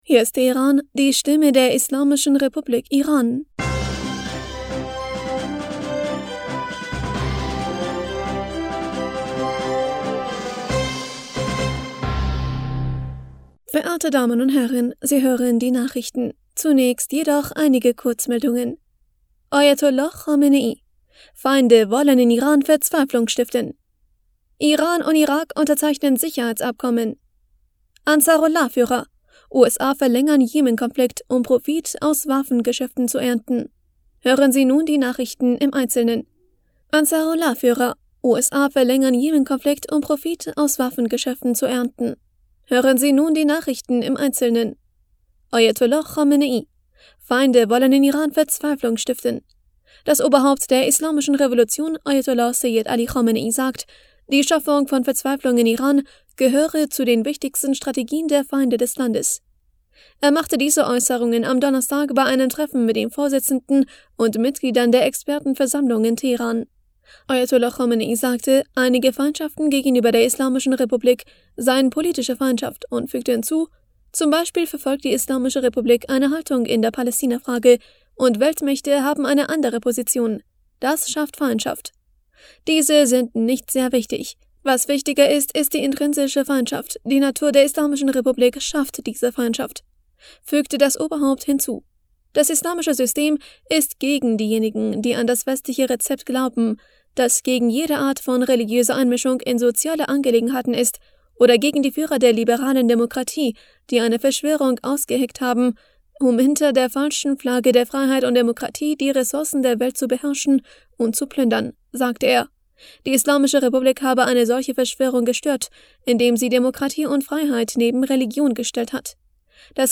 Nachrichten vom 24. Februar 2023